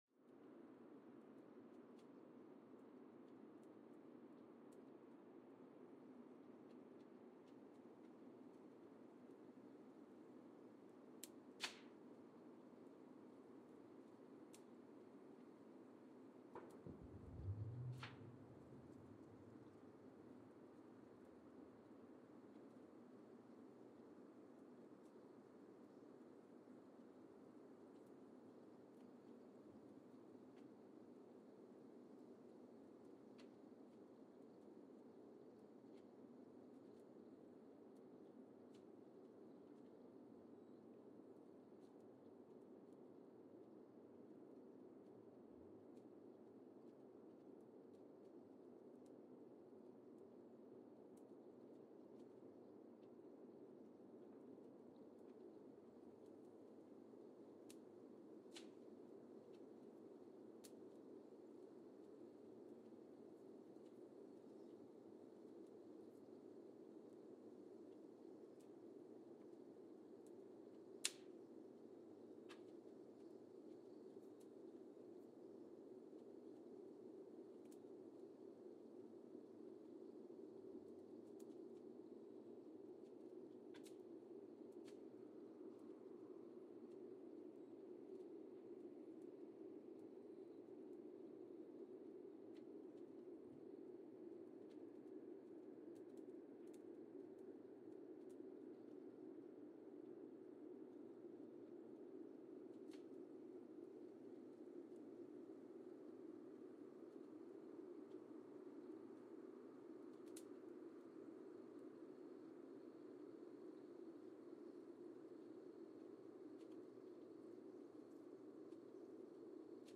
Mbarara, Uganda (seismic) archived on September 10, 2017
Sensor : Geotech KS54000 triaxial broadband borehole seismometer
Speedup : ×1,800 (transposed up about 11 octaves)
Loop duration (audio) : 05:36 (stereo)